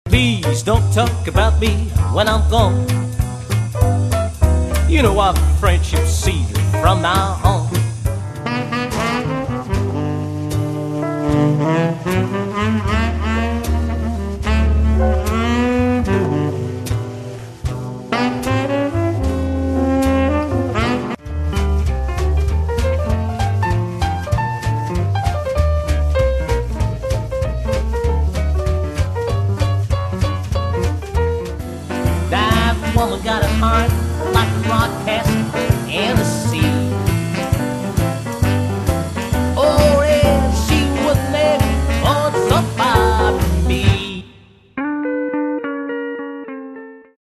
sextette de style Jazz Swing